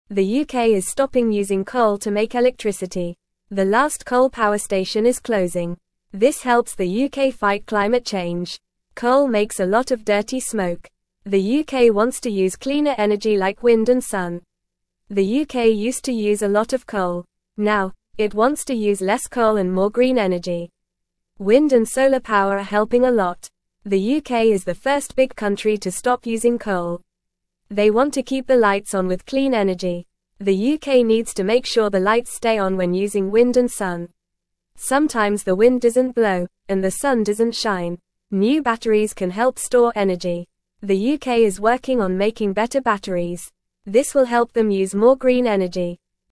Fast
English-Newsroom-Beginner-FAST-Reading-UK-Stops-Using-Coal-for-Electricity-Fights-Climate-Change.mp3